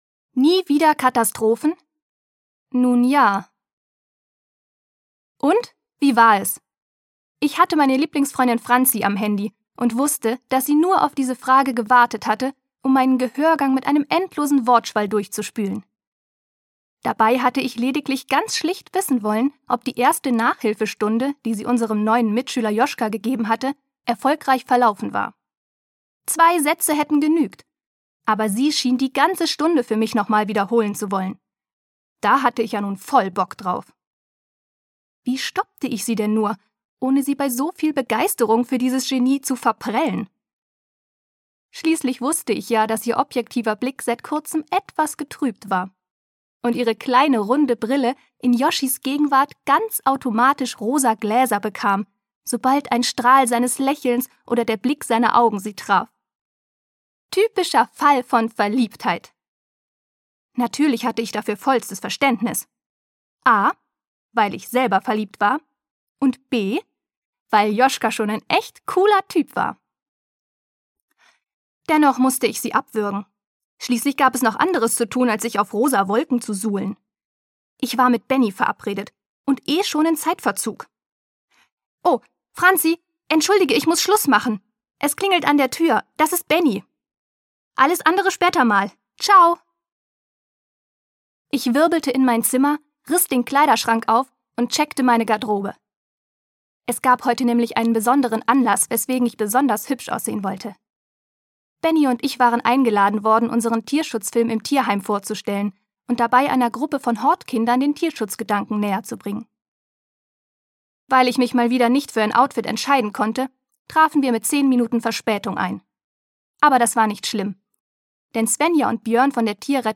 Freche Mädchen: Herzensdinge & allerletzte Katastrophen - Bianka Minte-König - Hörbuch